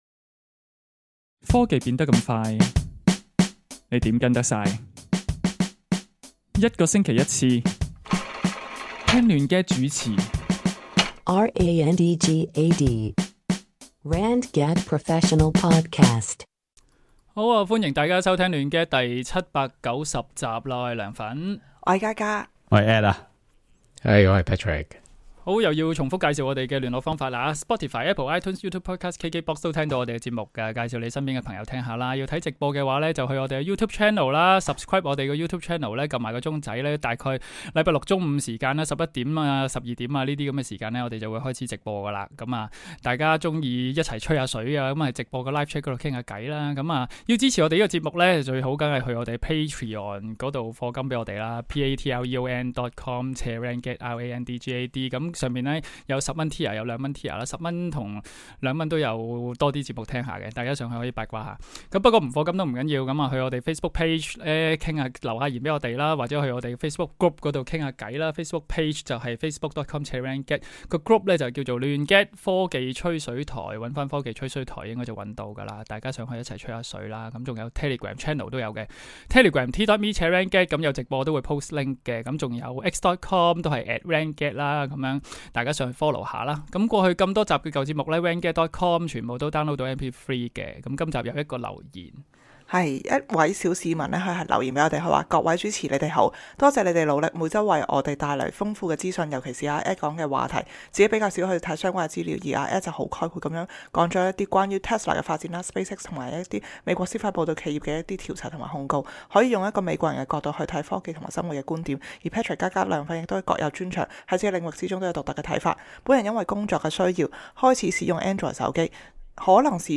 搜羅最新科技資訊、數碼產品，由四位主持，從不同立場出發，以專業角度分析，每星期一集既網上電台節目 - 亂gad！